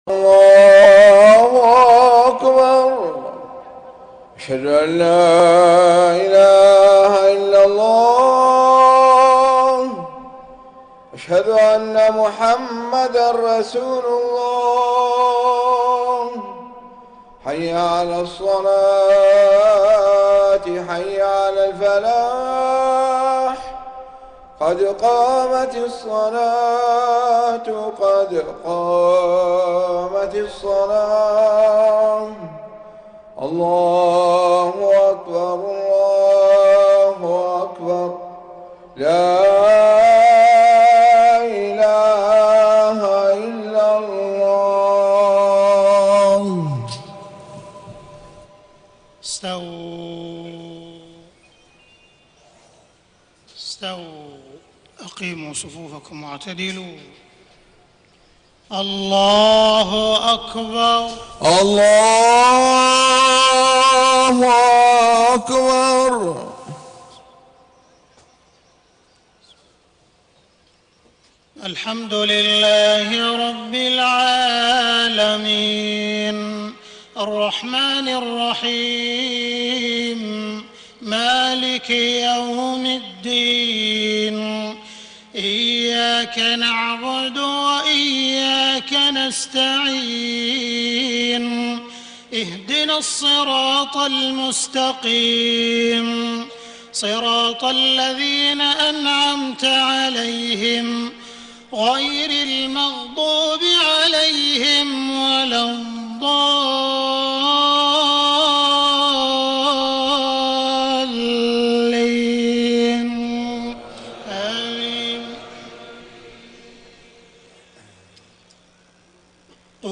صلاة المغرب 26 صفر 1431هـ من سورة الزمر 10-20 > 1431 🕋 > الفروض - تلاوات الحرمين